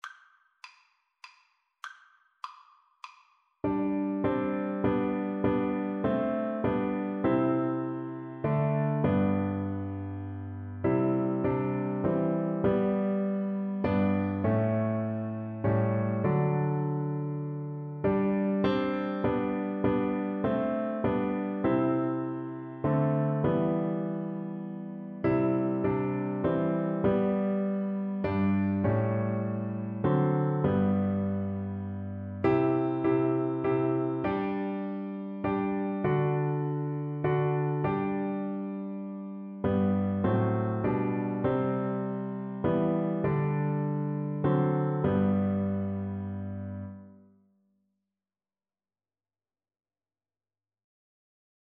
Classical Trad. Faith of our Fathers Flute version
Flute
Faith Of Our Fathers Hymn
G major (Sounding Pitch) (View more G major Music for Flute )
3/4 (View more 3/4 Music)
Classical (View more Classical Flute Music)